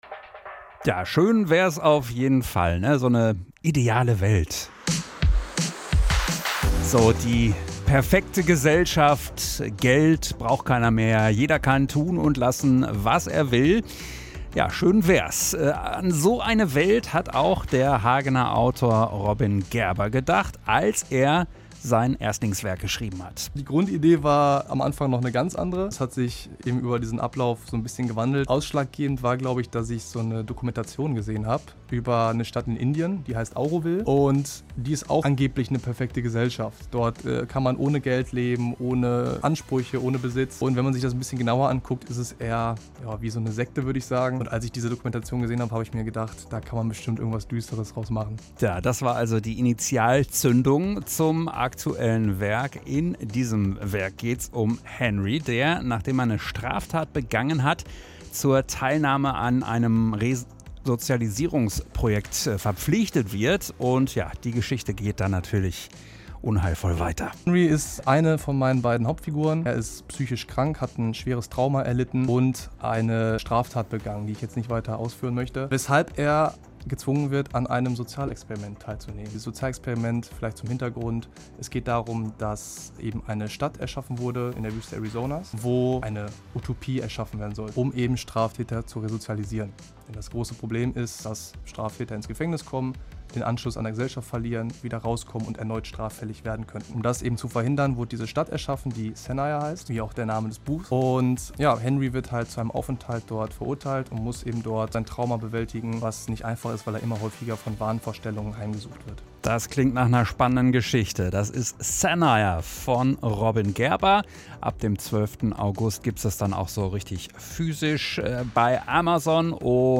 Veröffentlicht: Donnerstag, 31.07.2025 15:15 Anzeige MITSCHNITT AUS DER SENDUNG play_circle Abspielen download Anzeige
off-air-record-studio-1-1314.mp3